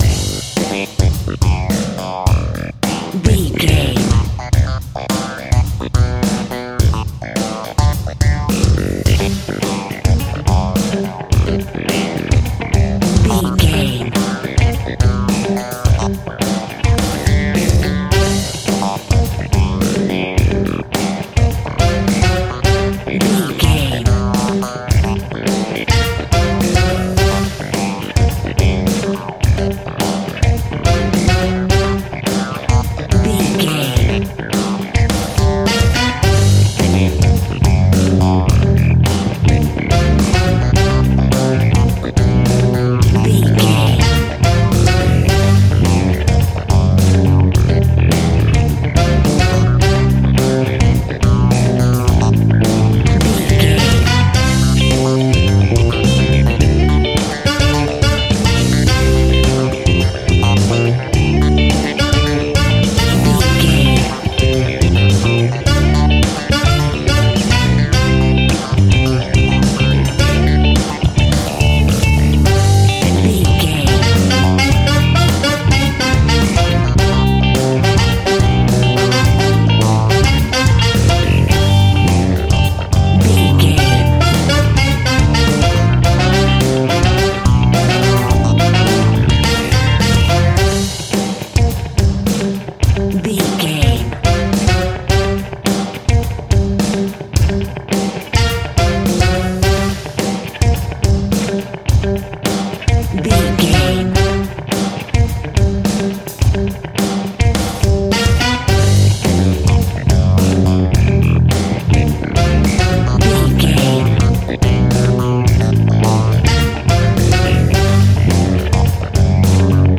Funky hip hop music.
Ionian/Major
electric guitar
bass guitar
drums
hammond organ
fender rhodes
percussion